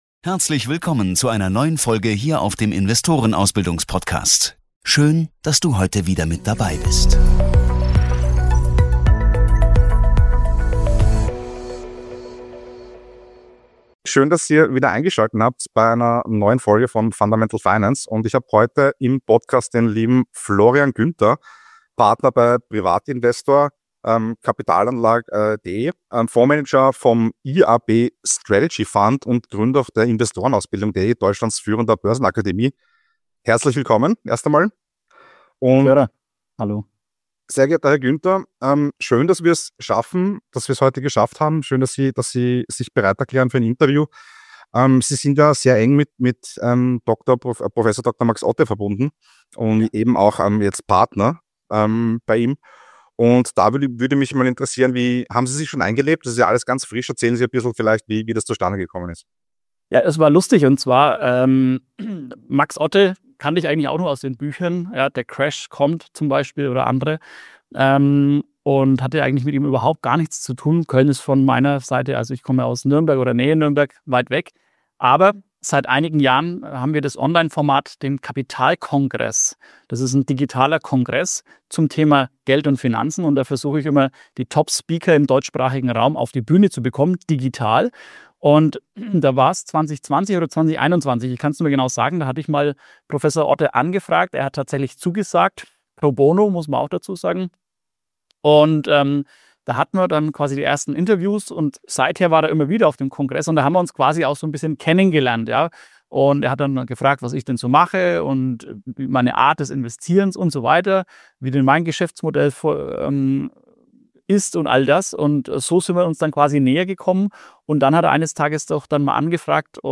analysiert im Gespräch mit Fundamental Finance messerscharf die aktuellen Herausforderungen von Deutschland bis zu den USA. Doch es gibt auch Lichtblicke: Entdecke seine Einschätzungen zu Gold, Silber, Aktien und den Investment-Chancen für 2025.